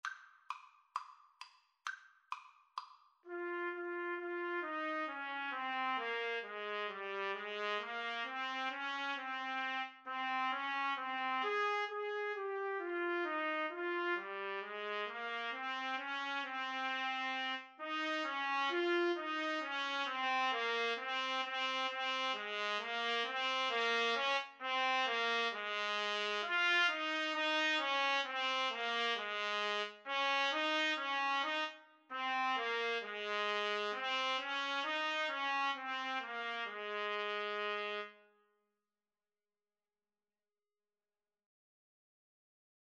The melody is in the minor mode.
Slow two in a bar feel = c. 66
Trumpet Duet  (View more Easy Trumpet Duet Music)